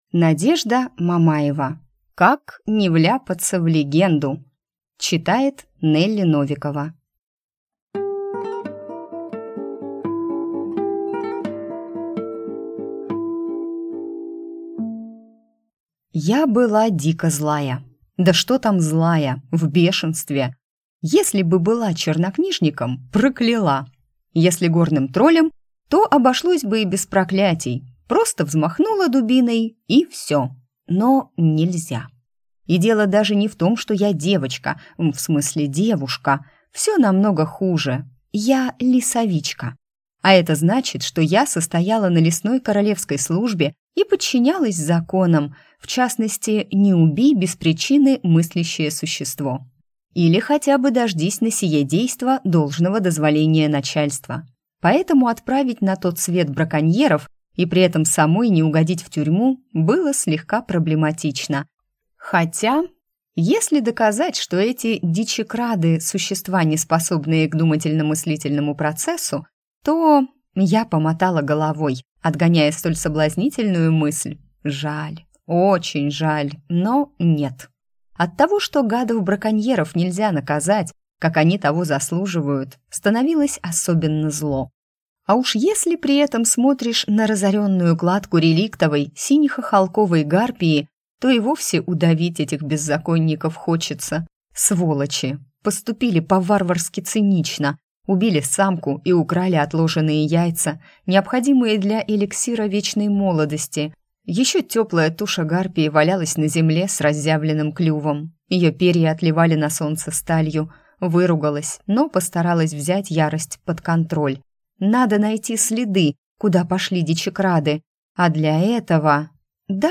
Аудиокнига Как не вляпаться в легенду | Библиотека аудиокниг